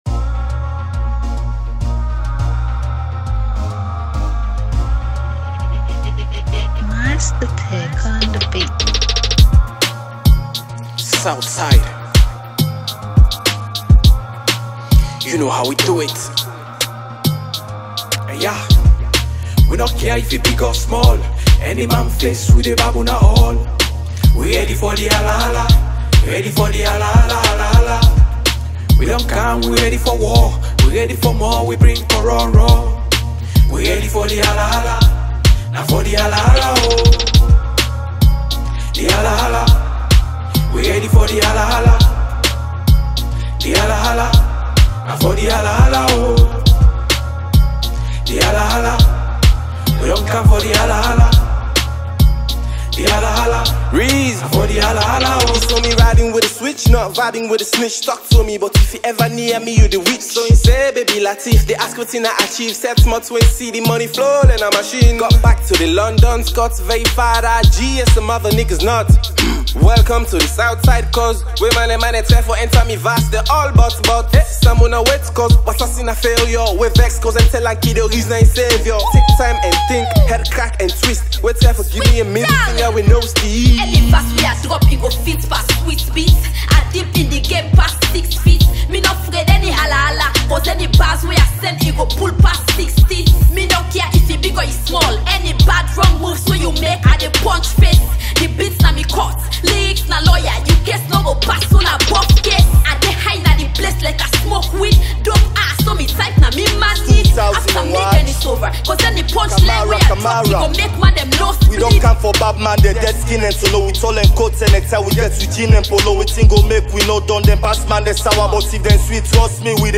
rap group